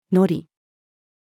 乗り-female.mp3